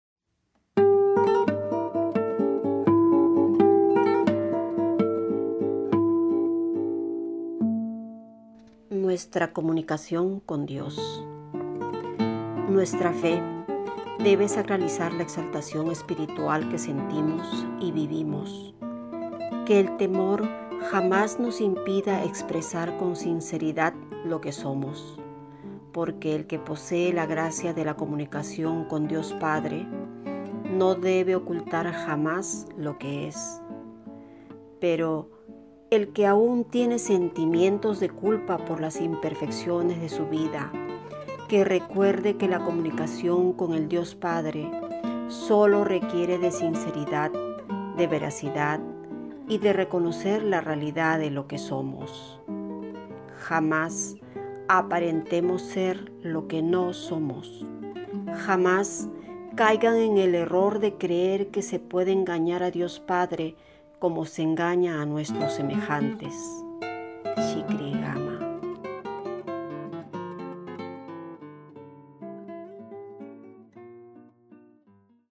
Ceremonia de Gratitud 1993